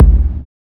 KICKSPACE.wav